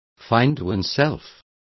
Also find out how verse is pronounced correctly.